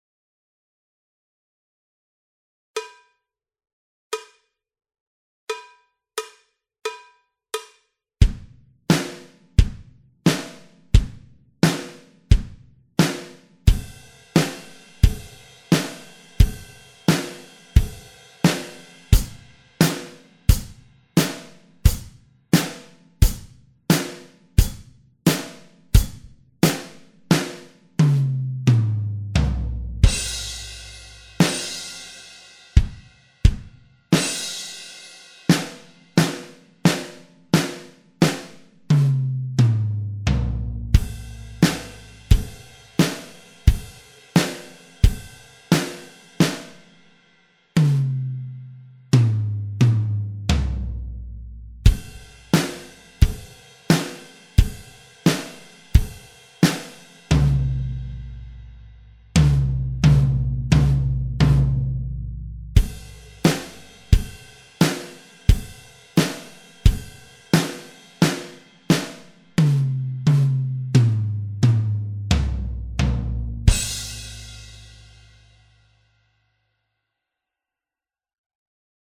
schnell